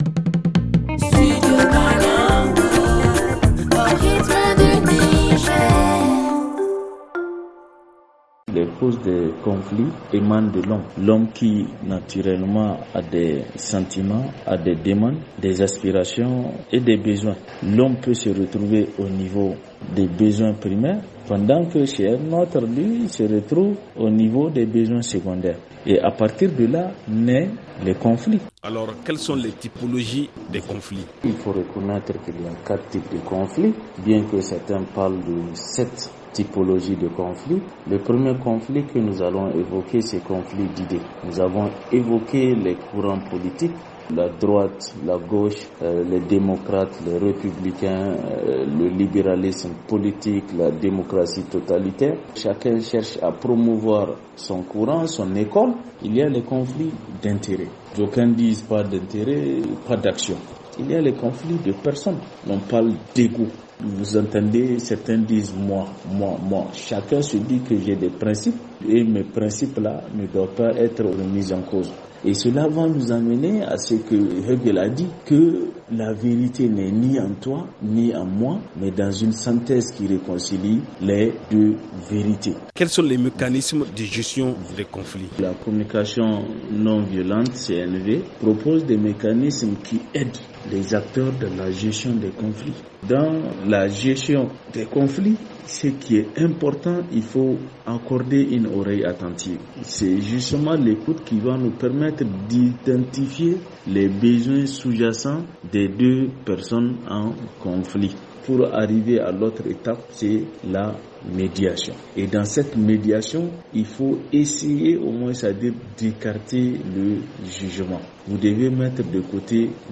Il est joint au téléphone